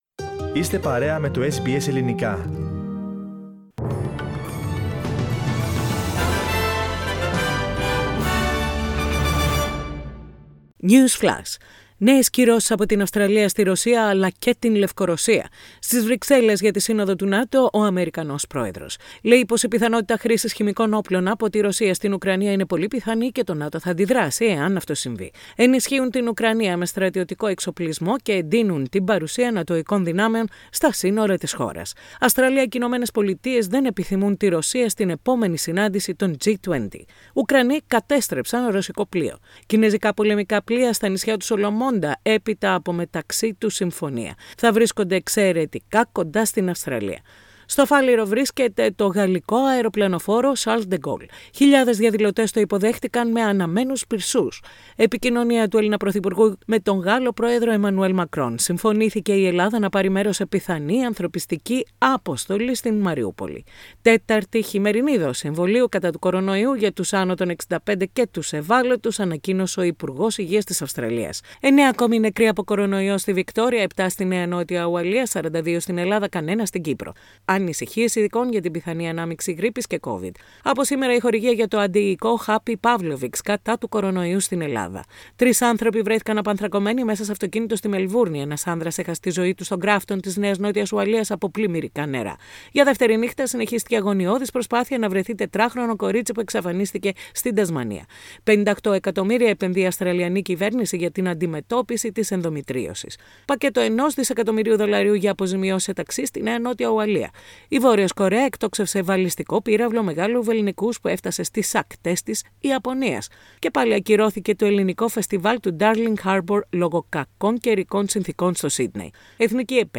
News Flash - Σύντομο δελτίο ειδήσεων - Παρασκευή 25.3.22